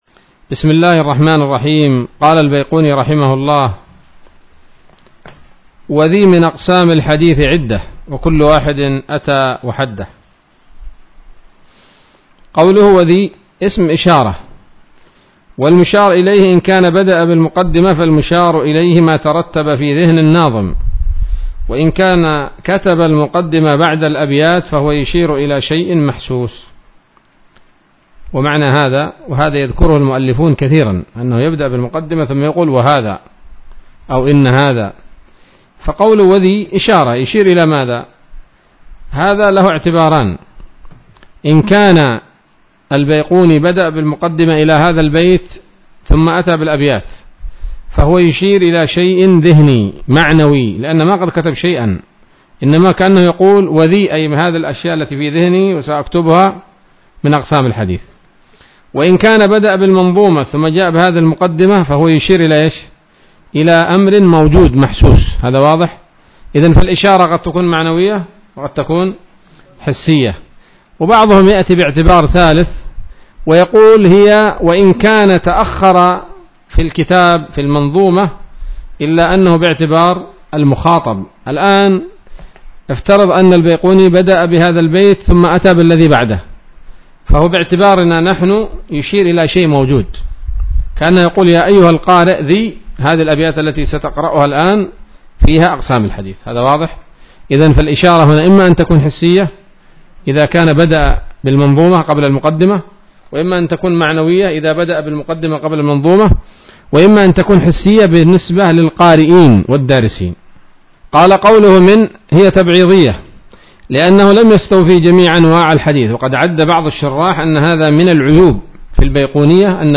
الدرس الخامس من الفتوحات القيومية في شرح البيقونية [1444هـ]